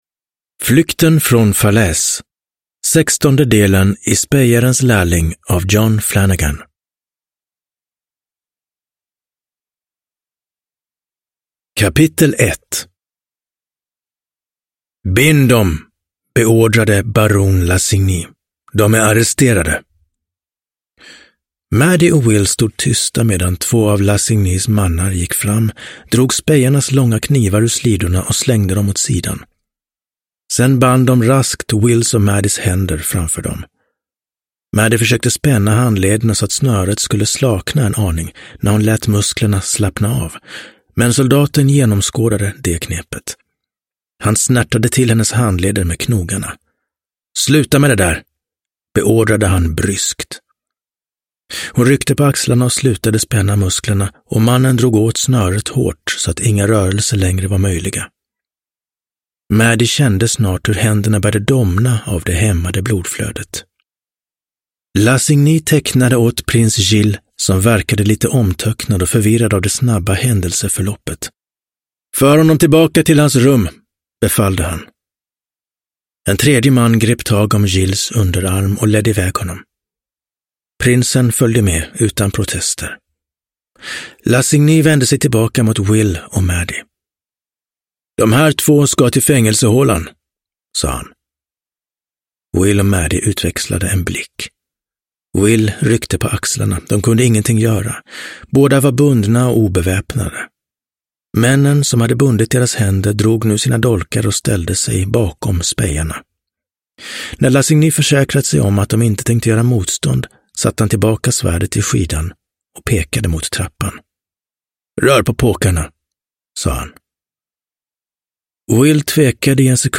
Flykten från Falaise – Ljudbok – Laddas ner